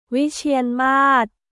ウィチアンマーッ